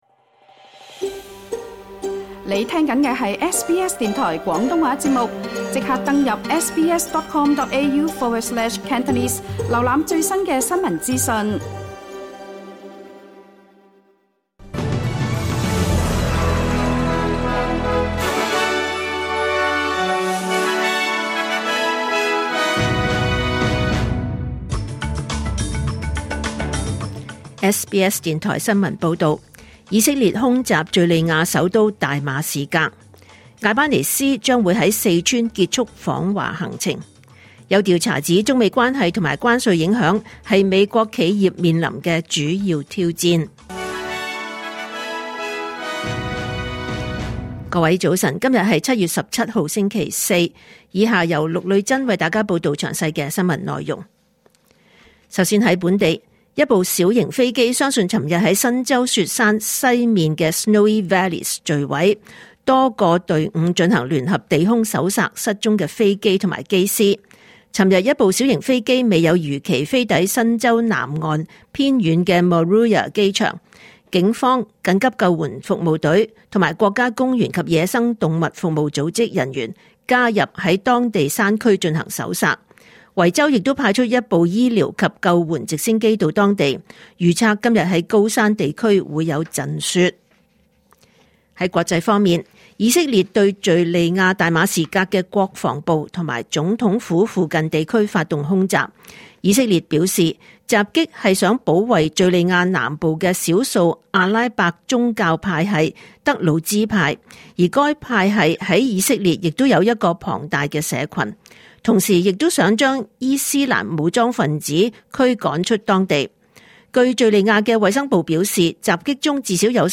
2025年7月17日SBS廣東話節目九點半新聞報道。